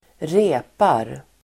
Uttal: [²r'e:par]